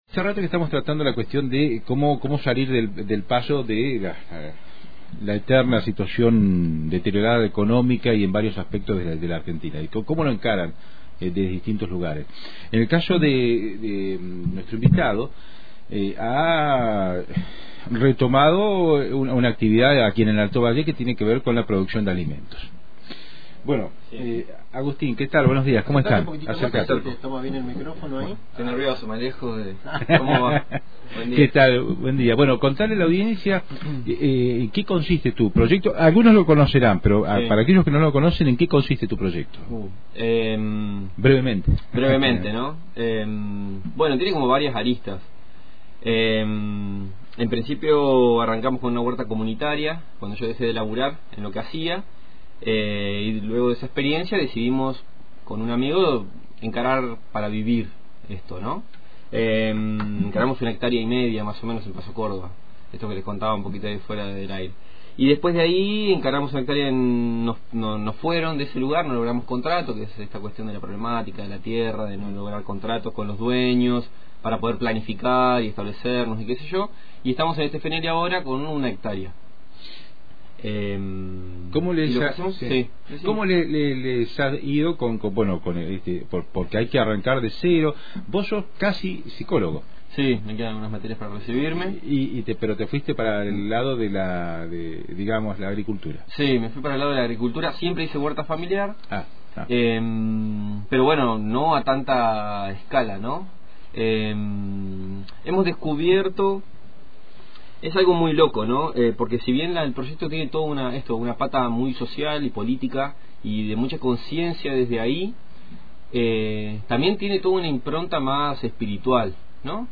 Conciencia, sustentabilidad y autogestión en un proyecto de huerta agro-ecológica. En diálogo con Antena Libre